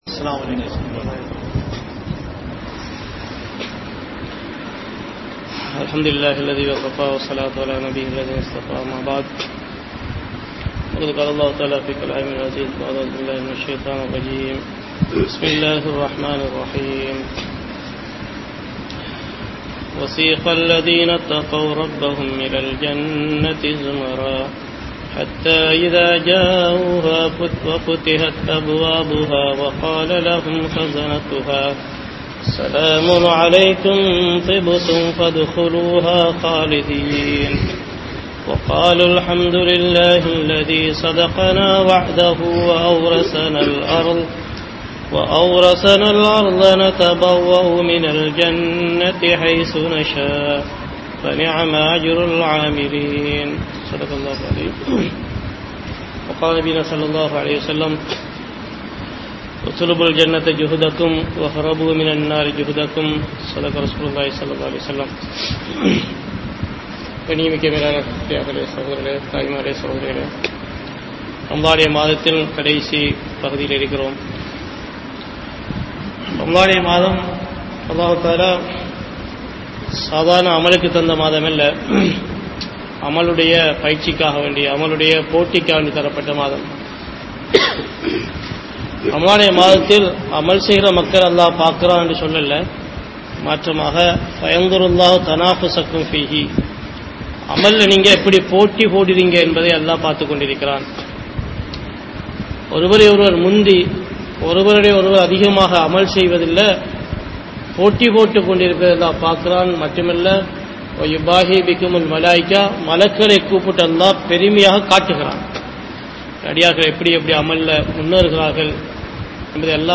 Ullaththai Paarpavan Allah (உள்ளத்தை பார்ப்பவன் அல்லாஹ்) | Audio Bayans | All Ceylon Muslim Youth Community | Addalaichenai
PachchaPalli Jumua Masjidh